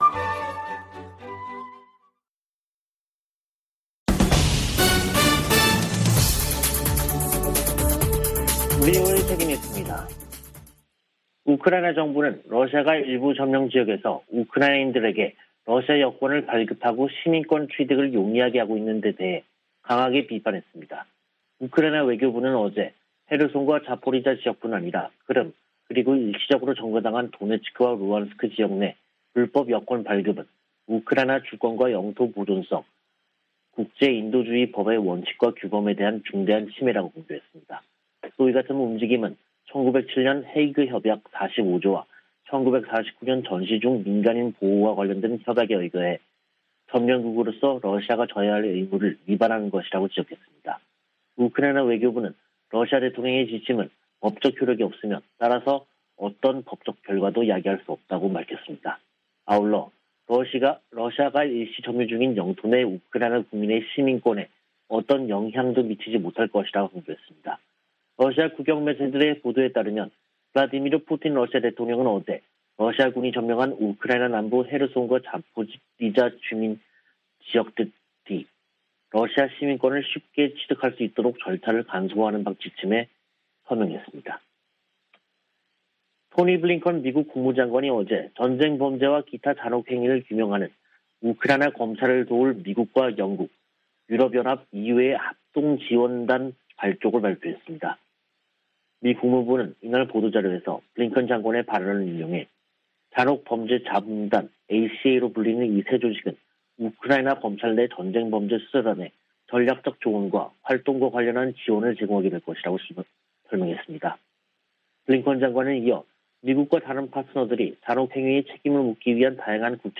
VOA 한국어 간판 뉴스 프로그램 '뉴스 투데이', 2022년 5월 26일 2부 방송입니다. 미 국무부 고위 관리가 북한의 탄도미사일 발사를 규탄하면서도 인도적 지원을 여전히 지지한다는 입장을 밝혔습니다. 유엔은 북한의 탄도미사일 발사가 긴장만 고조시킨다며 완전한 비핵화를 위한 외교적 관여를 촉구했습니다. 유엔 안보리가 새 대북 결의안을 표결에 부칩니다.